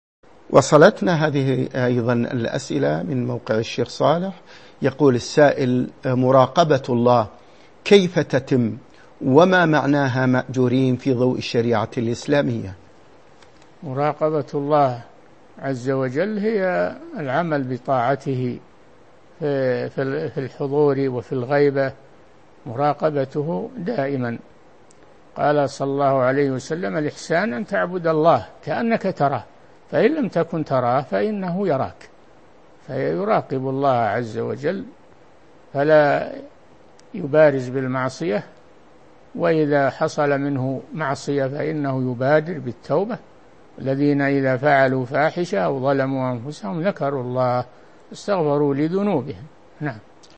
من مواعظ أهل العلم
Mono